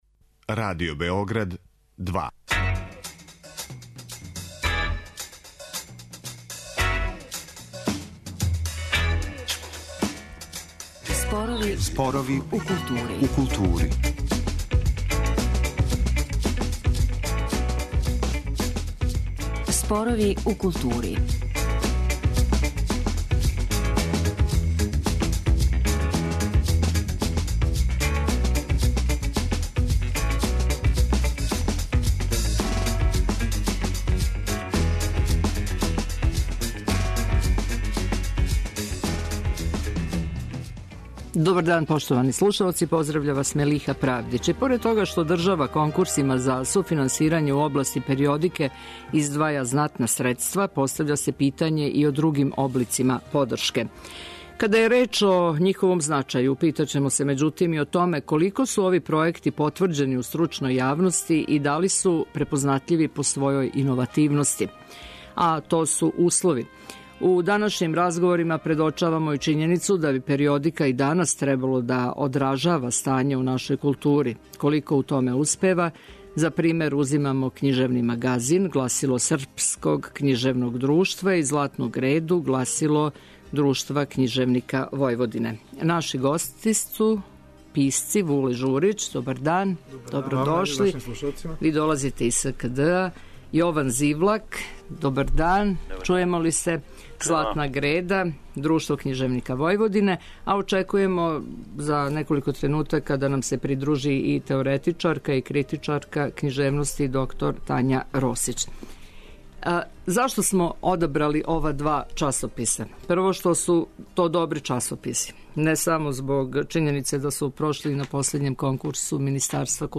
У данашњим разговорима предочавамо и чињеницу да би периодика и данас требало да одражава стање у нашој култури.